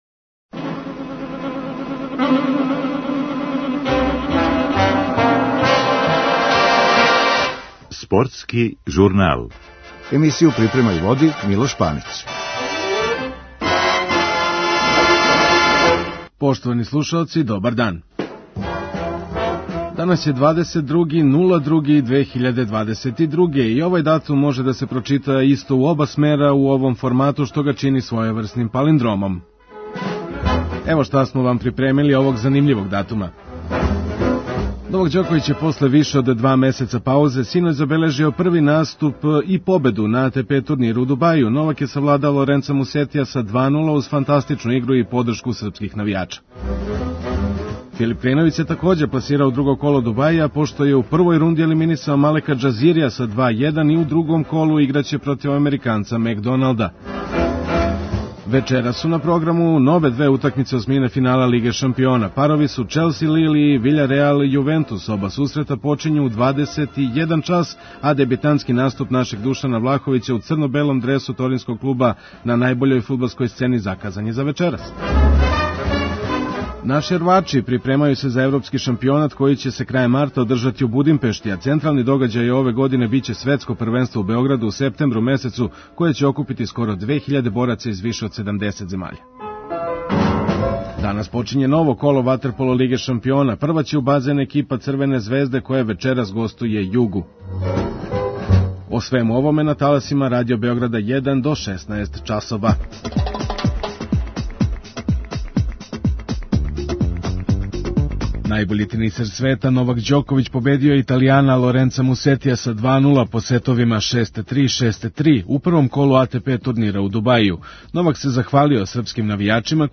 У емисији ћете чути Новакову изјаву после дуго очекиваног наступа.